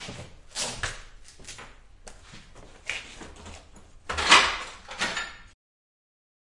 废弃的工厂金属后世界末日的回声" 大型金属门
描述：记录在爱尔兰都柏林的废弃工厂。使用Zoom H6和Rode NT4。
Tag: 空间 噪声 金属 工业 回声